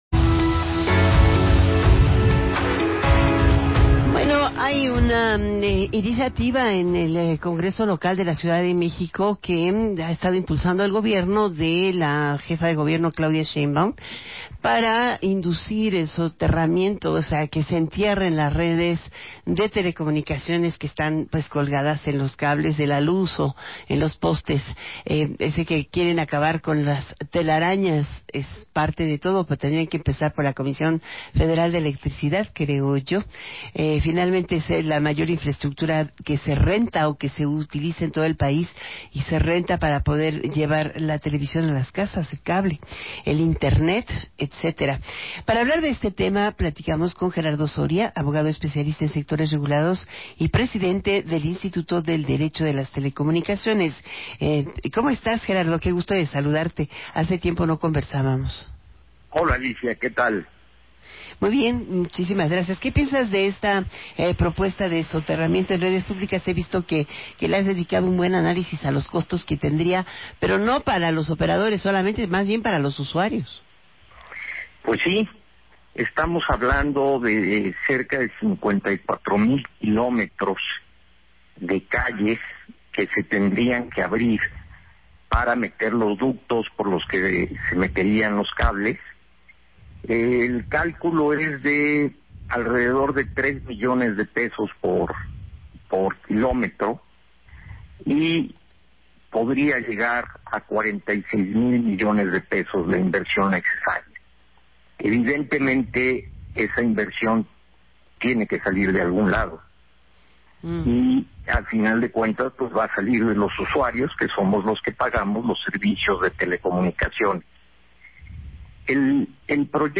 vía Stereo Cien